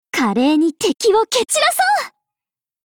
Cv-30609_warcry.mp3